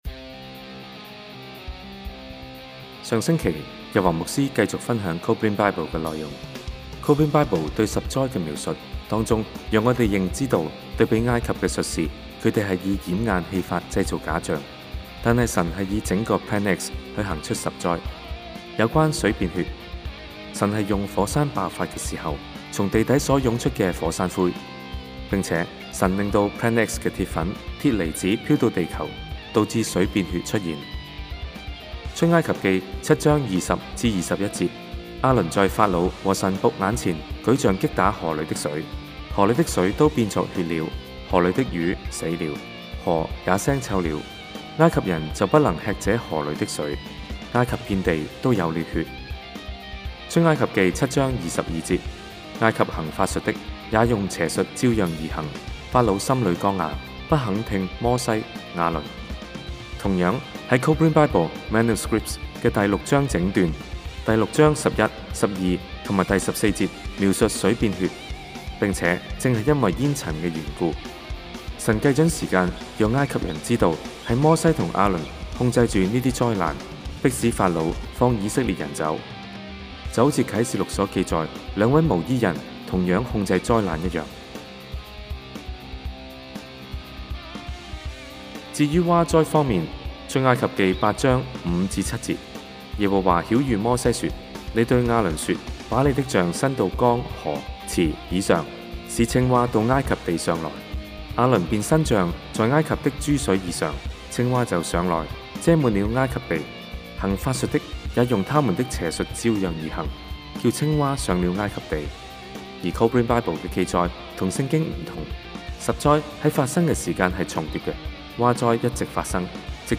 錫安教會主日信息 : 2012 榮耀盼望 vol. 135 (mp3 純聲音檔)